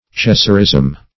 Cesarism \Ce"sar*ism\, n.